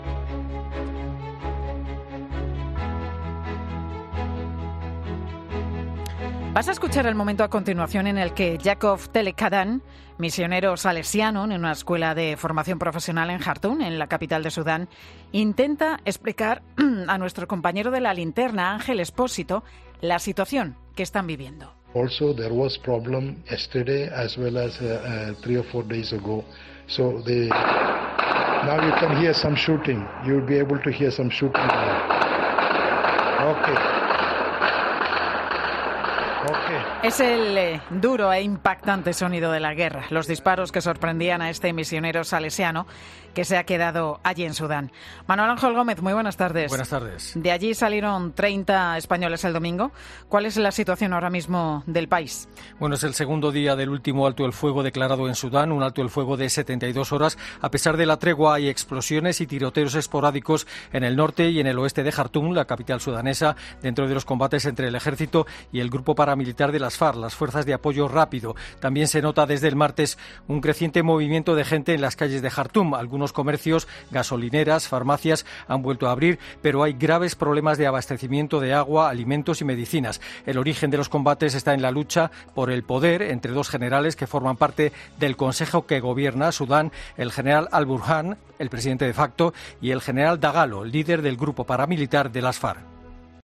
El audio de un misionero en Sudán a Expósito que pone los pelos de punta: el sonido real de la guerra
Al final del audo se escuchan los disparos, "ahora estas escuchando unos disparos... ok... ok... ok, paro... paro por los disparos", nos dice.
Es el sonido tal cual, real, de la guerra en Sudán.